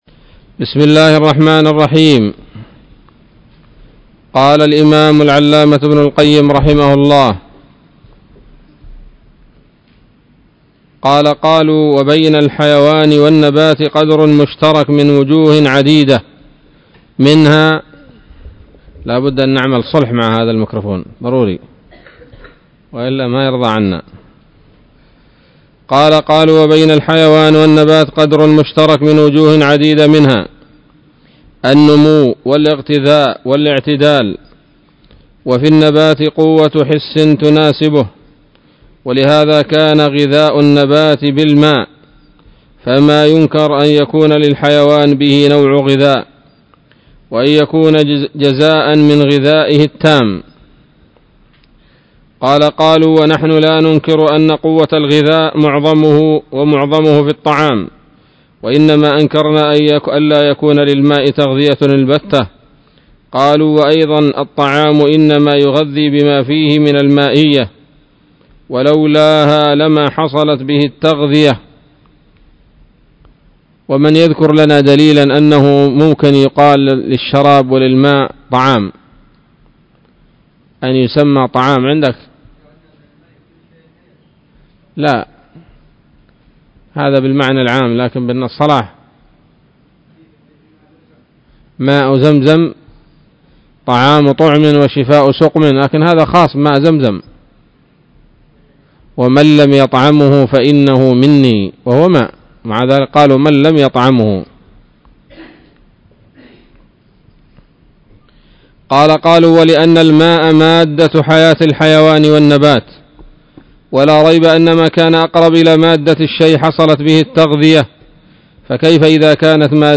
الدرس الثاني والستون من كتاب الطب النبوي لابن القيم